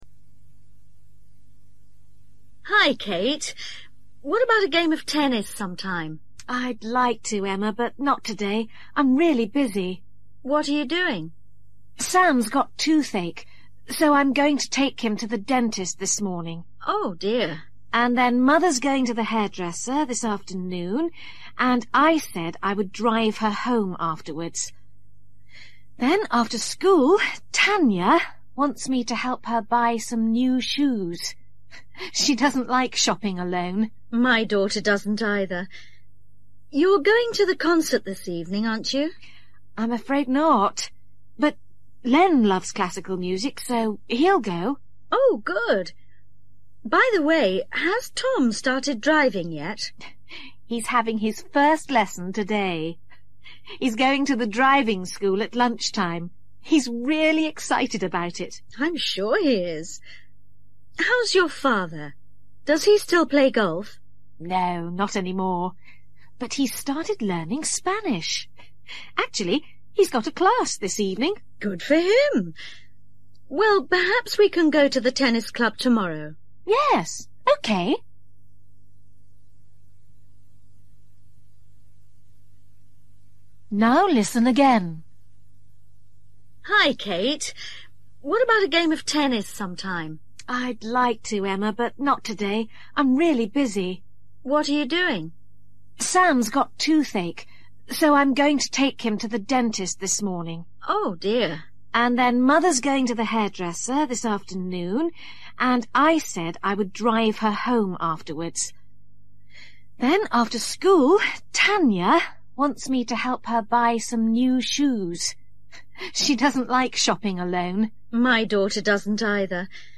Listen to Kate telling Emma about her family. Where is each person going today?